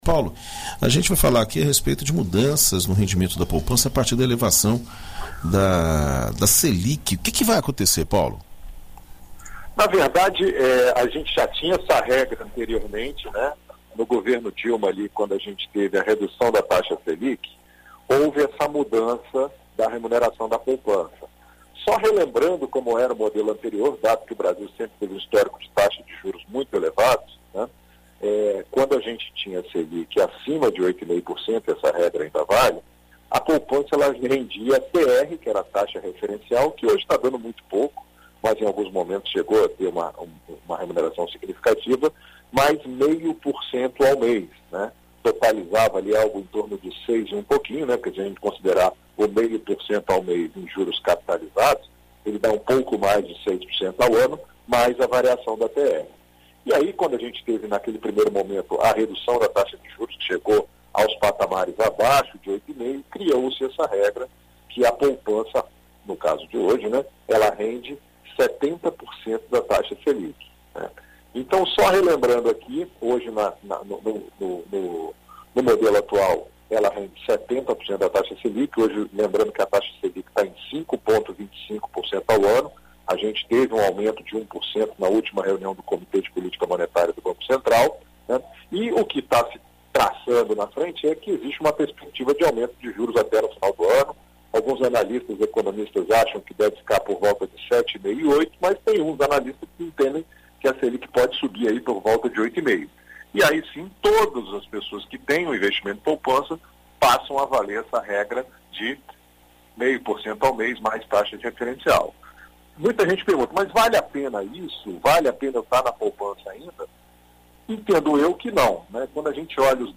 Na coluna Seu Dinheiro desta terça-feira (10), na BandNews FM Espírito Santo